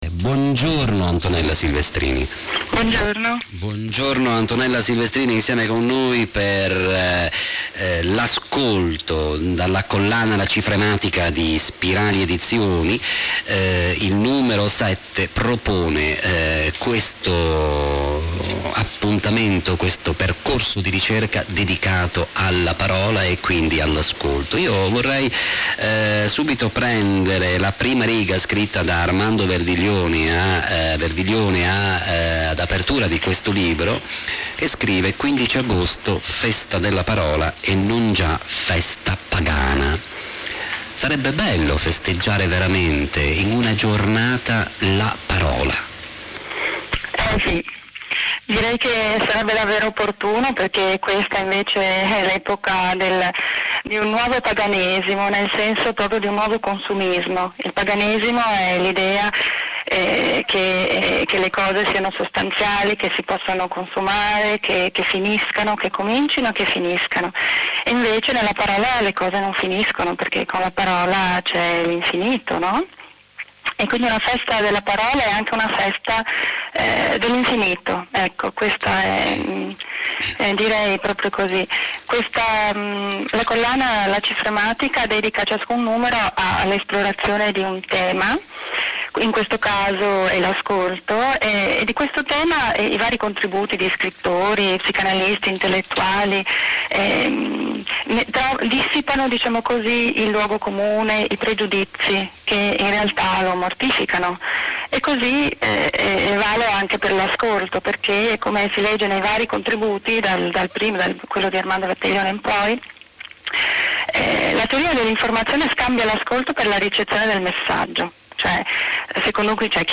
Intervista
in occasione della presentazione del libro L'ascolto